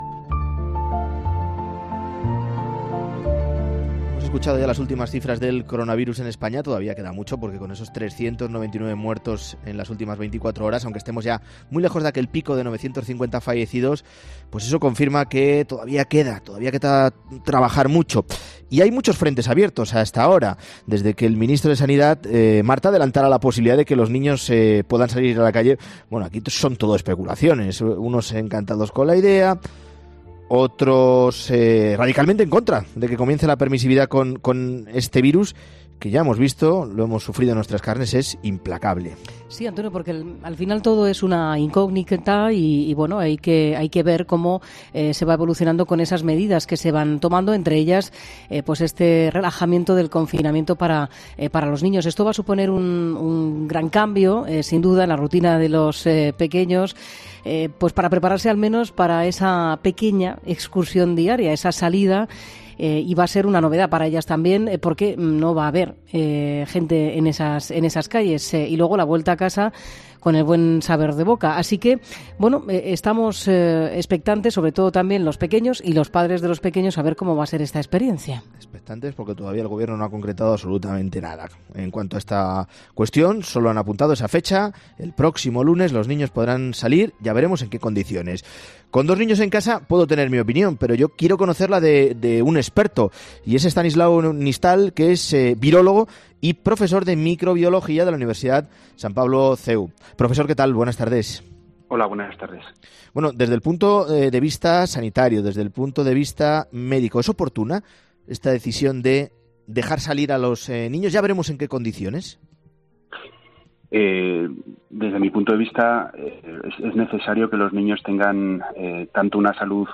Hemos entrevistado a un experto sobre la idoneidad o no de que esta excepción se ponga en marcha.